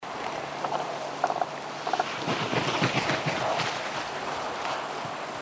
When preparing to take flight the cranes will make a
Preflightcall.
Whooping Crane